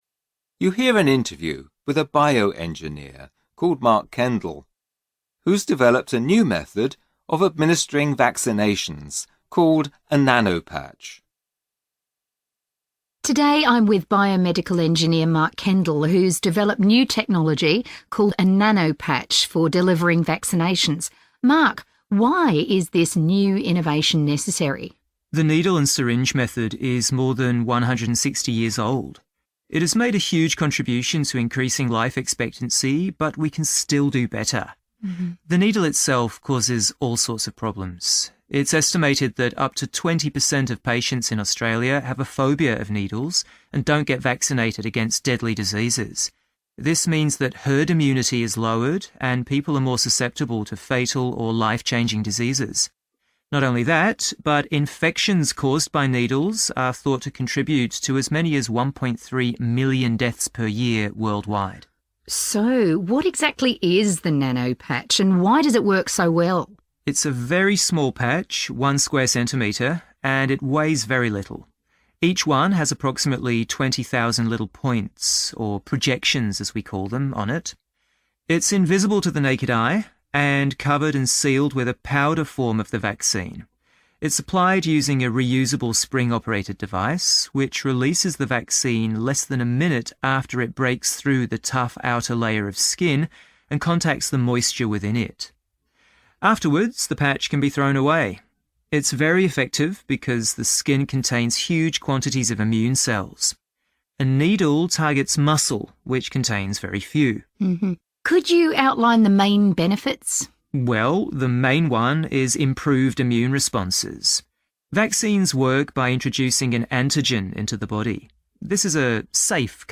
You hear an interview